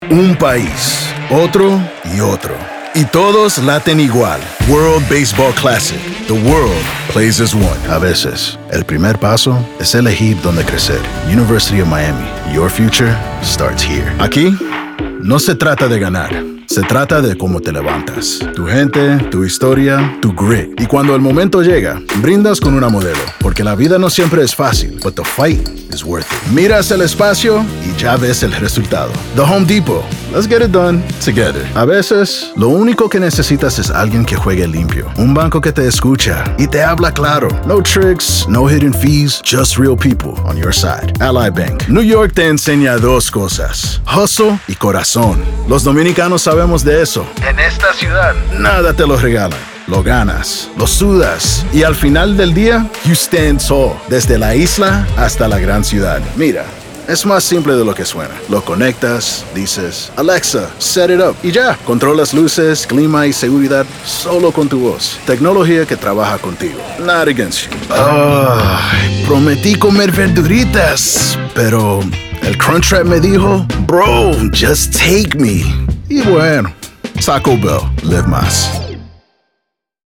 Eleve la esencia de su mensaje con un servicio de voz en off profesional y puntual.
Comercial
• Sala acondicionada con variedad de trampas de graves.
• Piso de ruido de -60dB